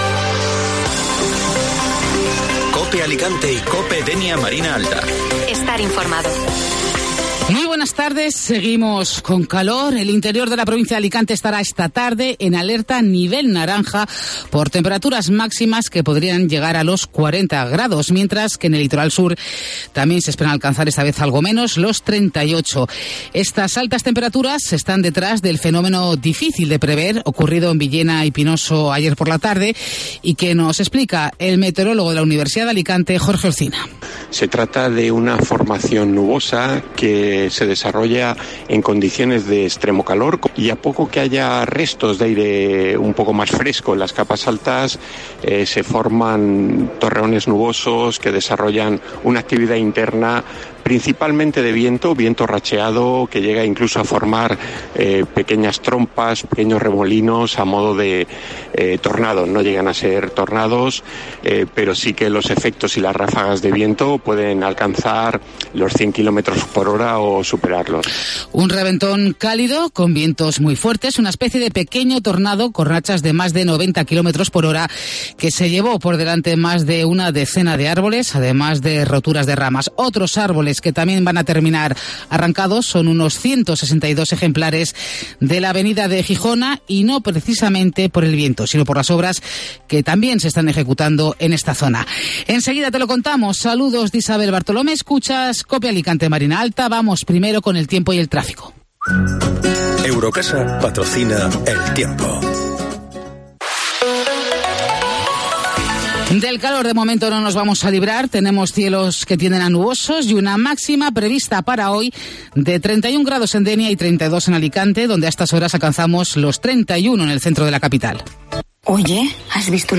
En Directo COPE ALICANTE